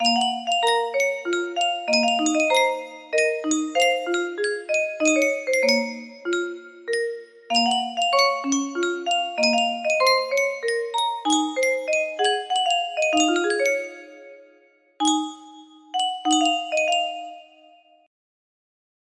Yunsheng Music Box - Schubert's Serenade Y630 music box melody
Full range 60